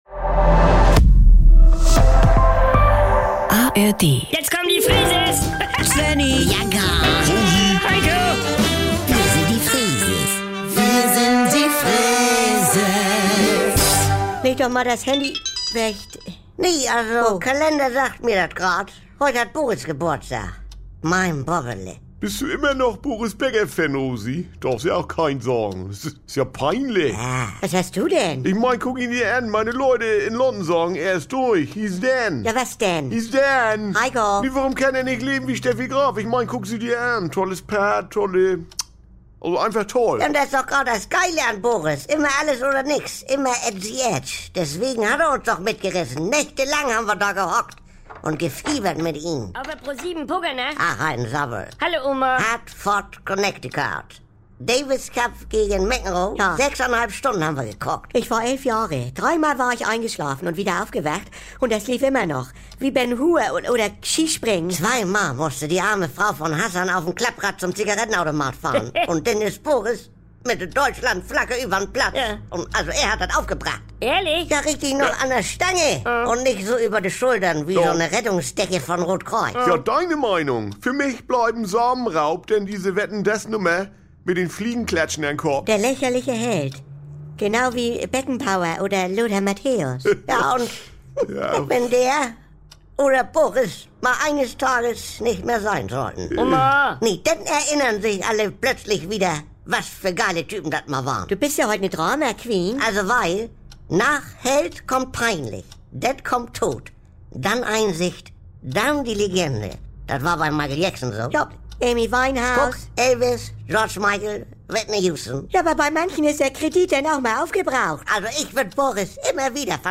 … continue reading 665 episoder # NDR 2 # Saubere Komödien # Unterhaltung # Komödie